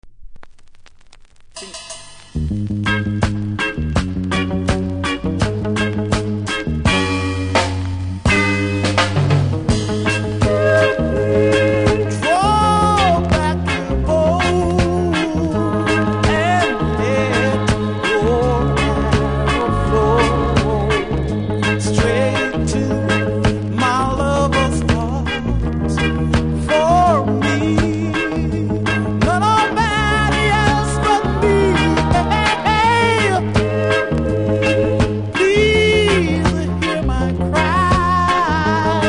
キズ多めですがノイズはそれほどもないので試聴で確認下さい。